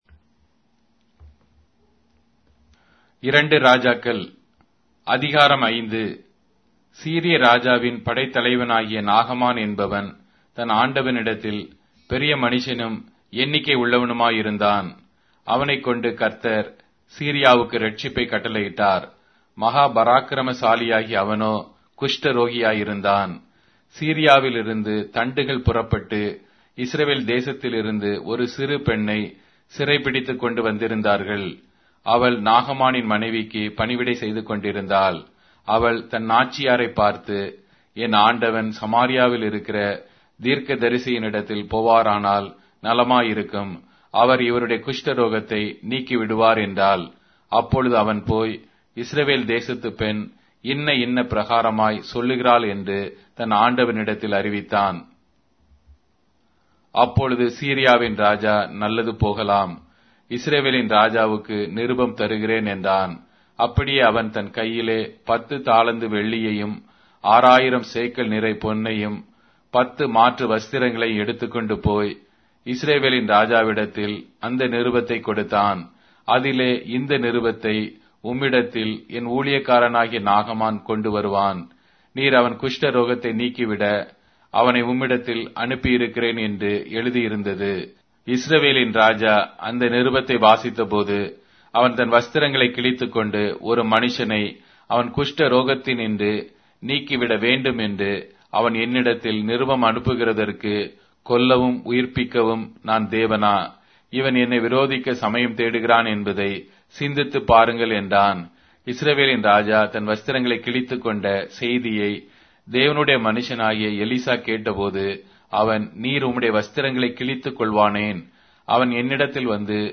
Tamil Audio Bible - 2-Kings 1 in Mhb bible version